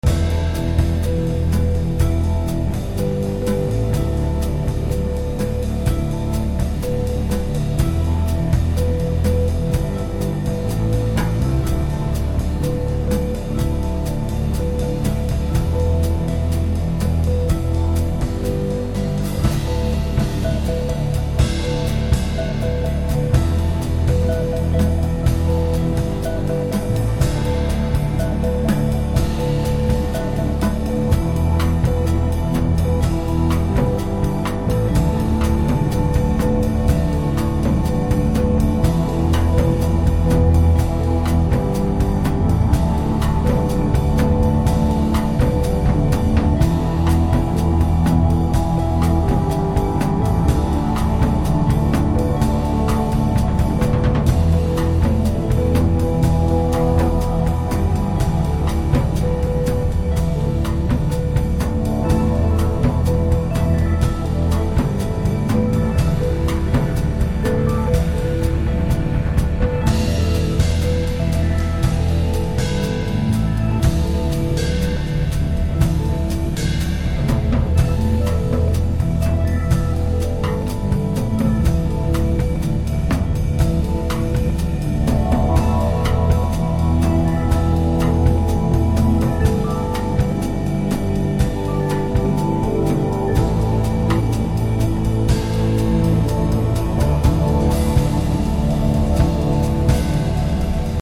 ジャズっぽい生ドラムを主体に濃厚なエレクトリック・グルーヴで肉付けした個性的かつ極上な2曲を収録！